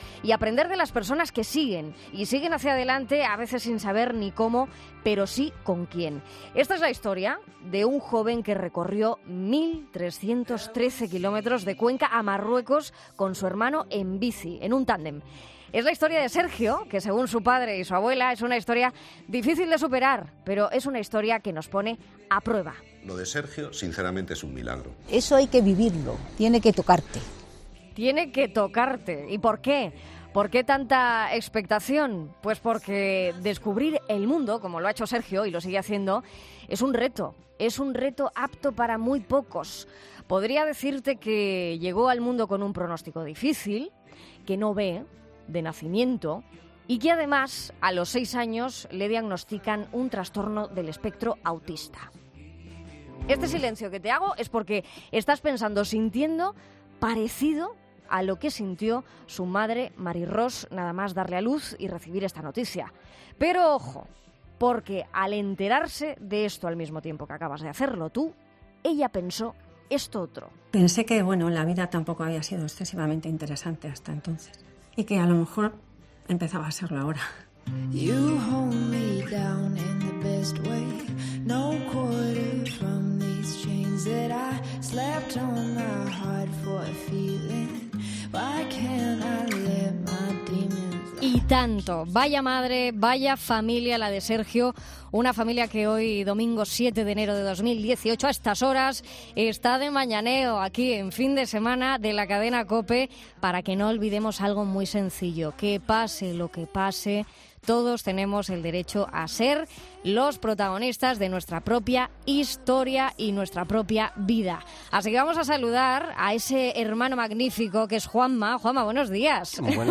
ESCÚCHALO en la entrevista de 'Fin de Semana'